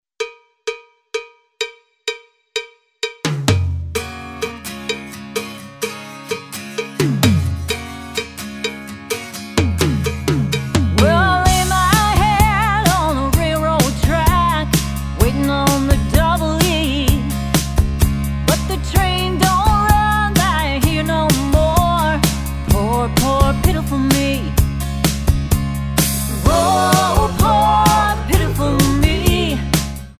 Tonart:C# Multifile (kein Sofortdownload.
Die besten Playbacks Instrumentals und Karaoke Versionen .